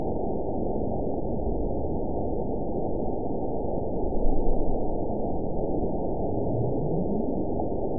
event 919900 date 01/28/24 time 01:48:57 GMT (1 year, 3 months ago) score 9.55 location TSS-AB02 detected by nrw target species NRW annotations +NRW Spectrogram: Frequency (kHz) vs. Time (s) audio not available .wav